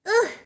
daisy_uh.ogg